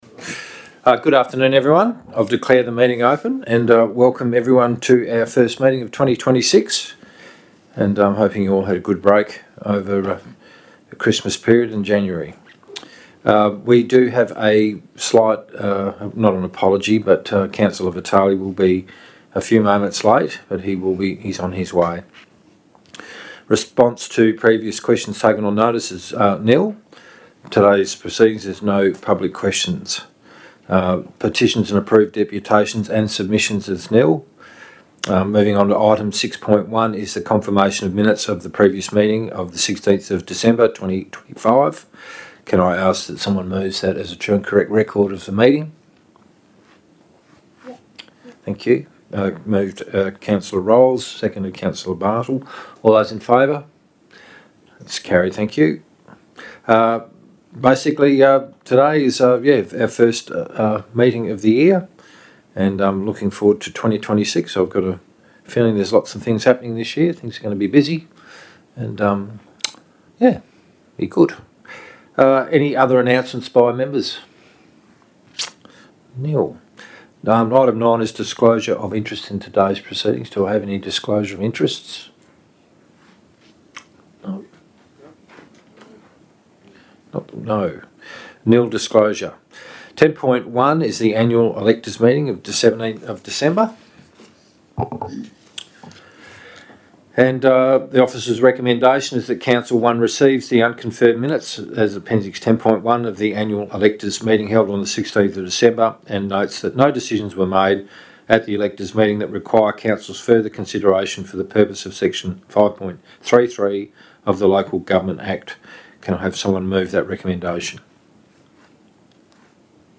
February Ordinary Council Meeting » Shire of Waroona